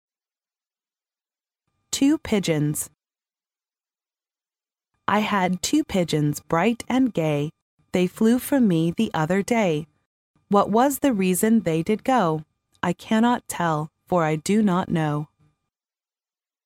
幼儿英语童谣朗读 第24期:两只鸽子 听力文件下载—在线英语听力室